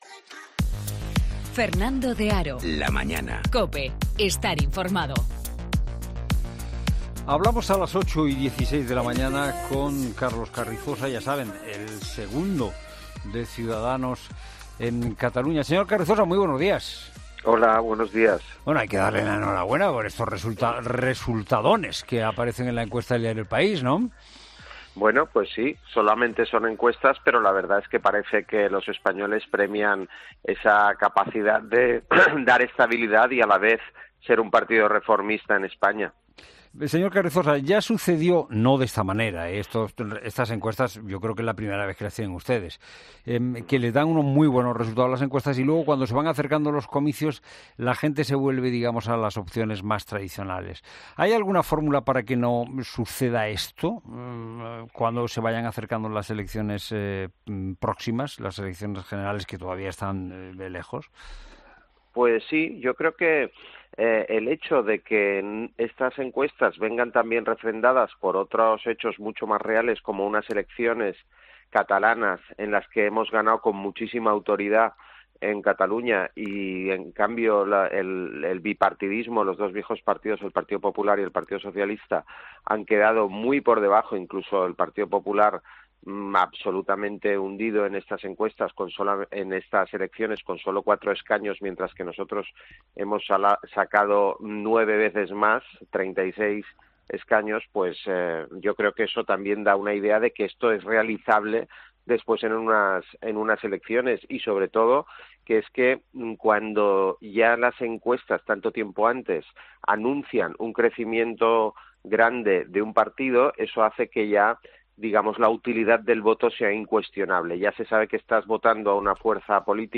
El portavoz parlamentario de Ciudadanos, Carlos Carrizosa